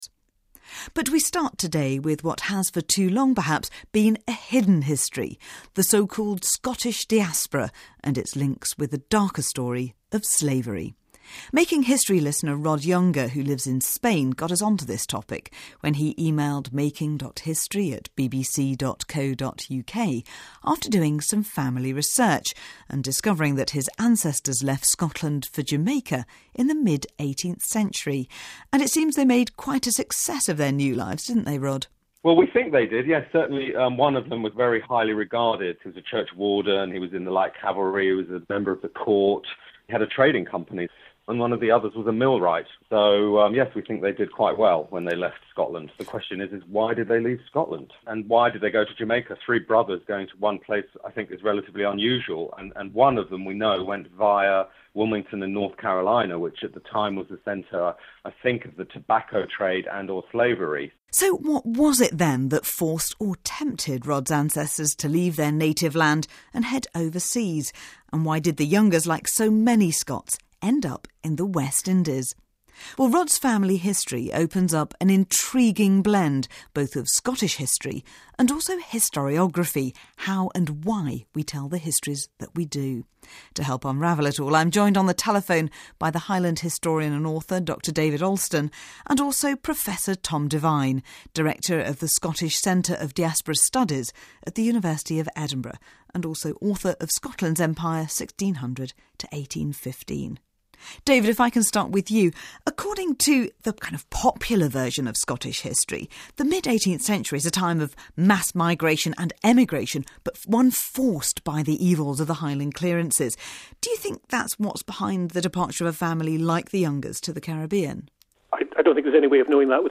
Both guests argued that Scots were heavily involved in slavery and that this has been downplayed by historians who have been more interested in telling a story of oppression — a "victim culture" as Professor Devine has it.